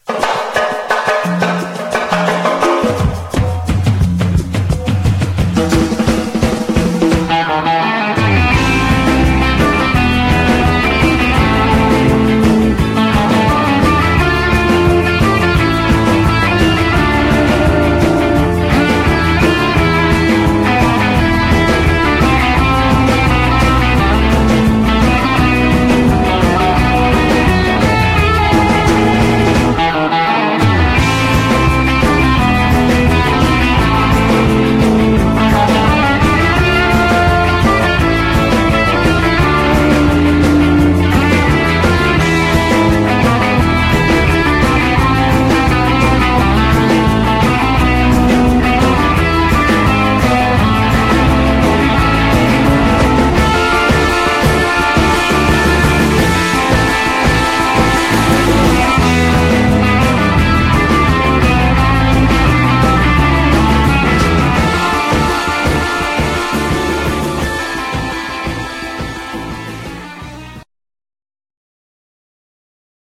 Japanese Eleki